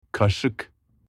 ترجمه و تلفظ “قاشق” به ترکی استانبولی: Kaşık
spoon-in-turkish.mp3